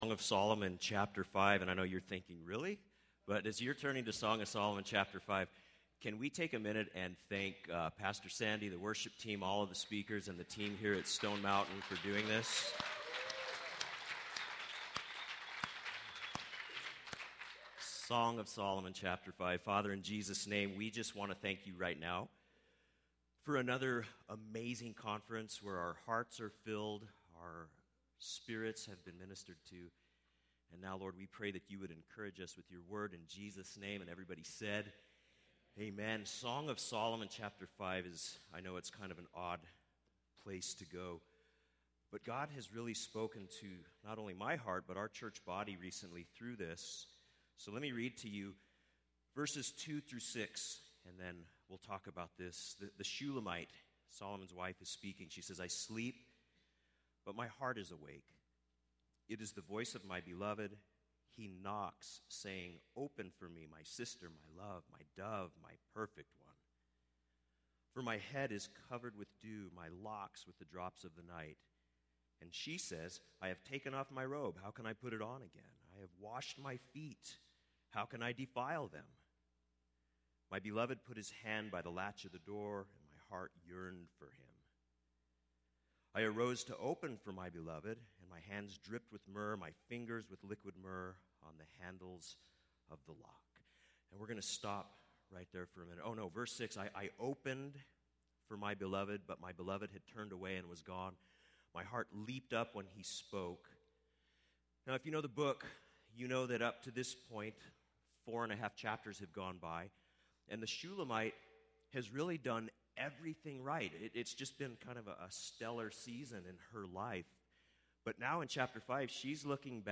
Home » Sermons » Session 9 – Devotion